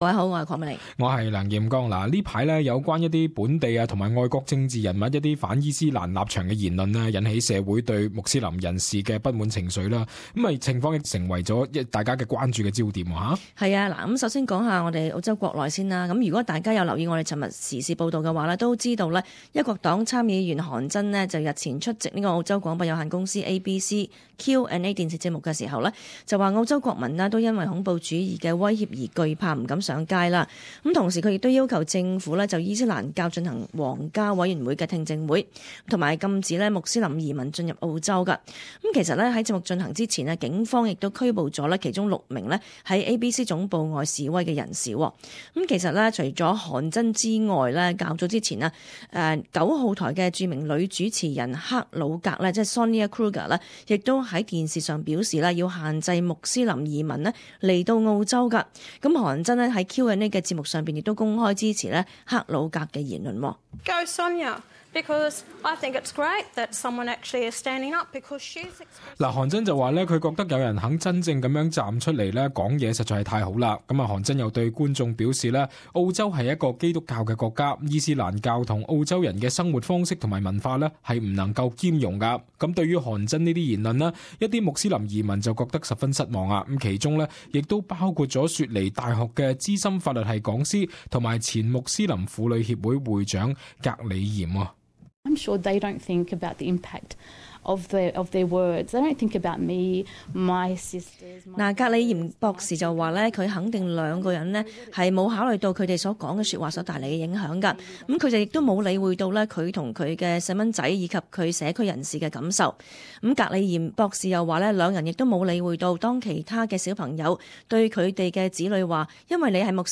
【時事報道】韓珍特朗普反伊斯蘭惹關注